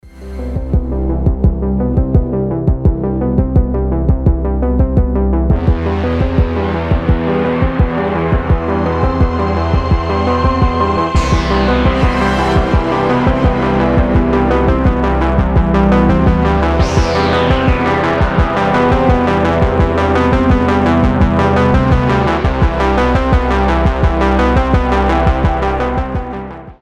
атмосферные
Electronic
без слов
из сериалов
synthwave
мистические
загадочные